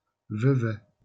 Vevey (French pronunciation: [vəvɛ]